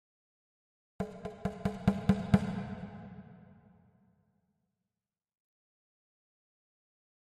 Drums Slow Hits 1 - Increasing Slow Hits, Thin Drum